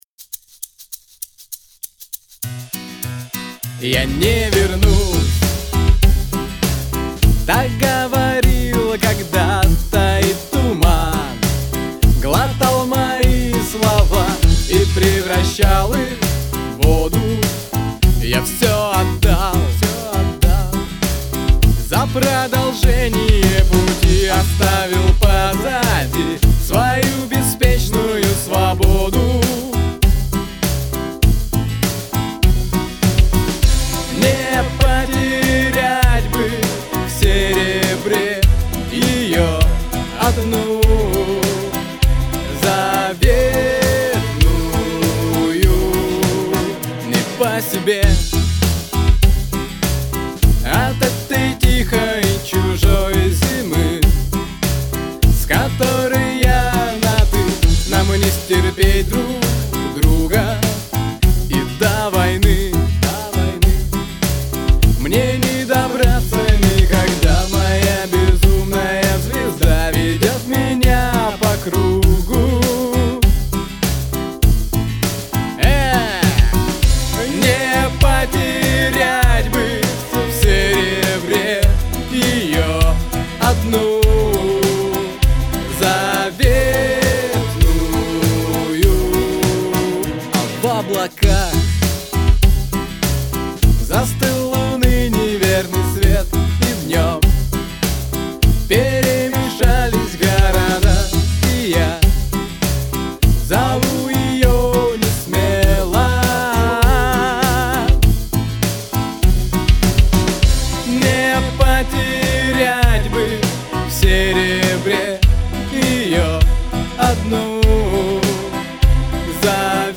Начал играться с обновкой RME fireface UC.